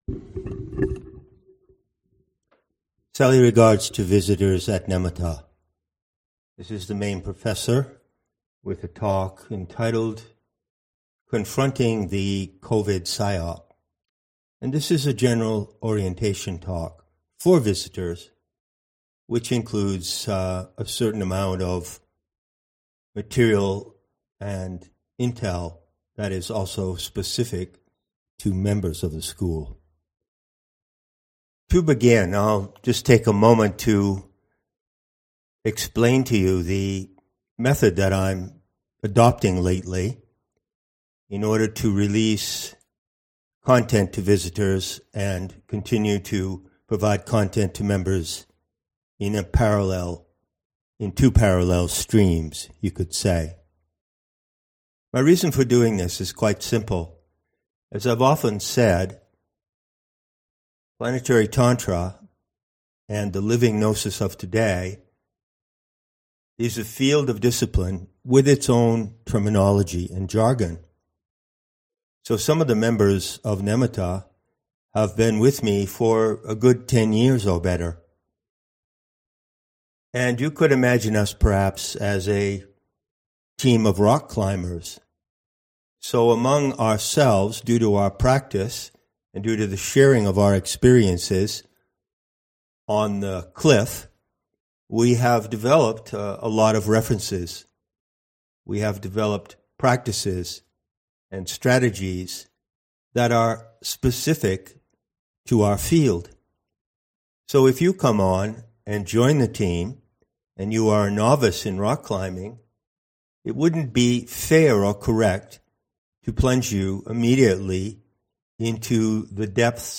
§ This is a general orientation talk setting out some aspects of the unique Gnostic perspective on the current crisis in the world-drama.